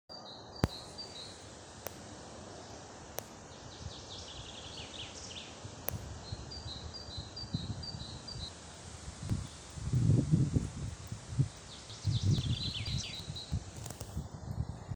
московка, Periparus ater
Ziņotāja saglabāts vietas nosaukumsRojas kapi
СтатусПоёт